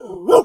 pgs/Assets/Audio/Animal_Impersonations/dog_2_large_bark_10.wav at master
dog_2_large_bark_10.wav